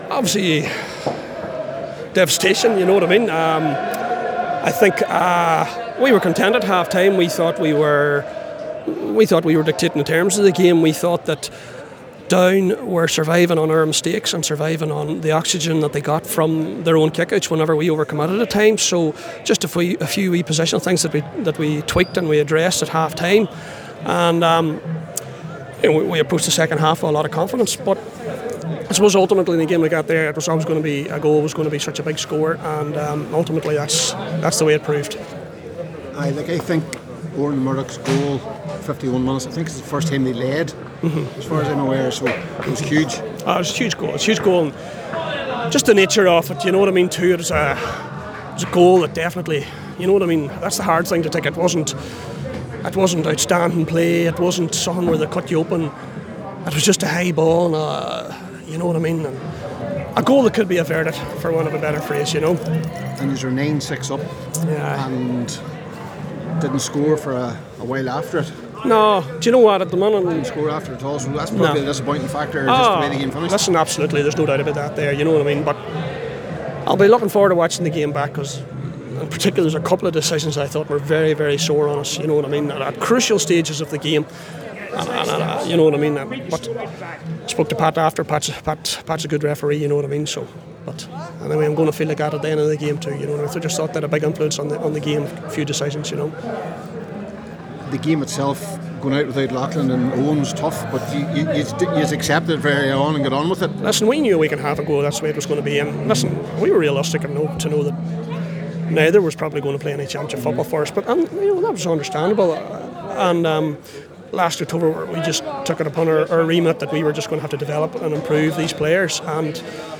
after the game: